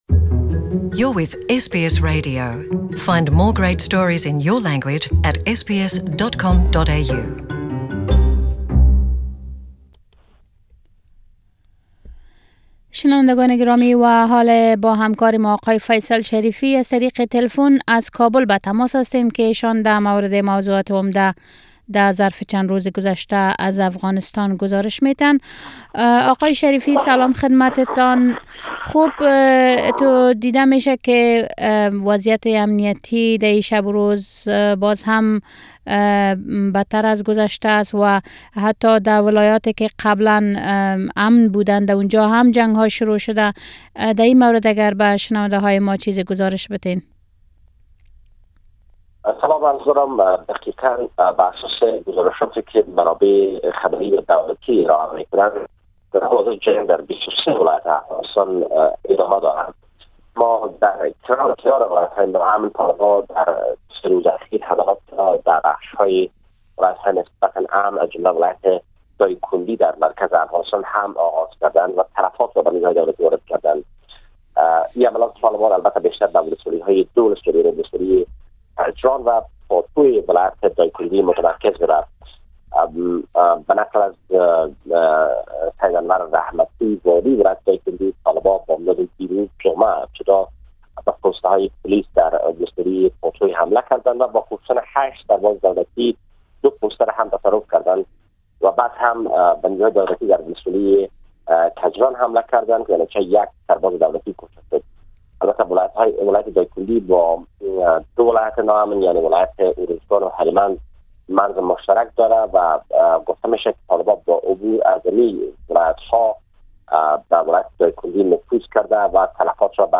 Report from Afghanistan 03/08/19
Our reporter from Kabul on the recent developments in Afghanistan